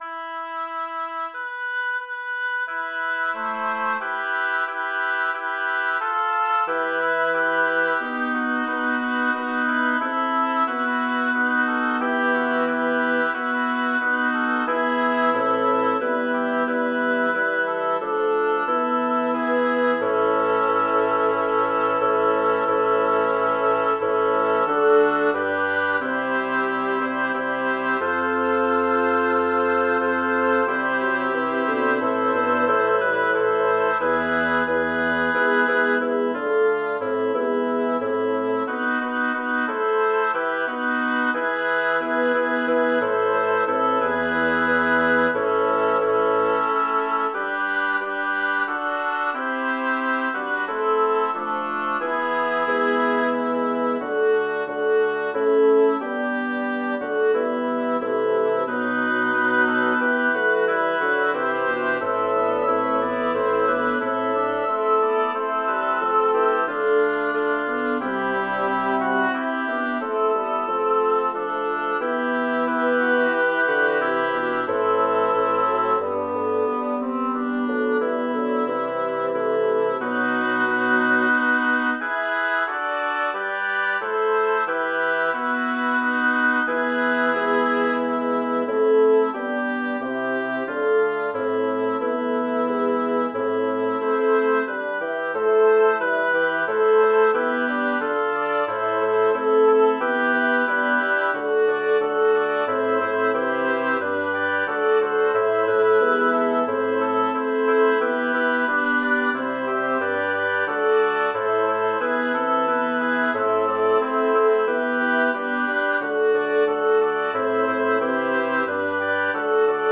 Title: Cum turba plurima Composer: Melchior Vulpius Lyricist: Number of voices: 6vv Voicing: SSATTB Genre: Sacred, Motet
Language: Latin Instruments: A cappella